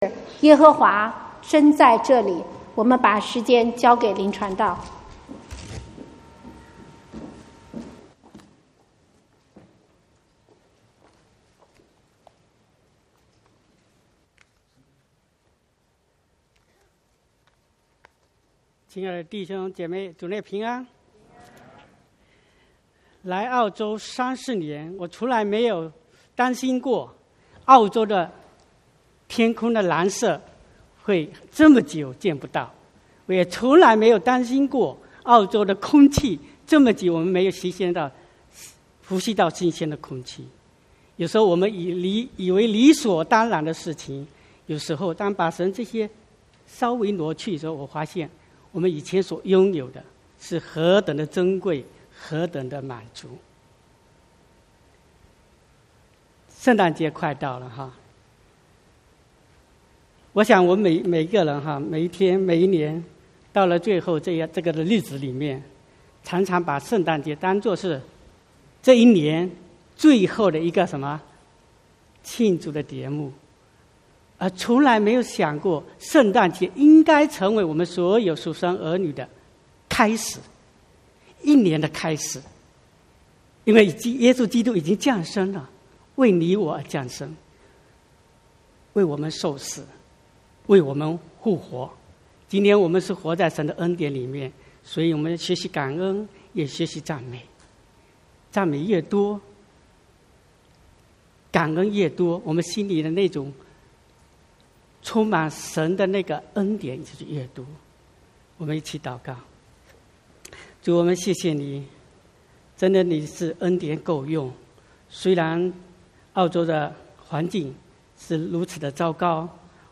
15/12/2019 國語堂講道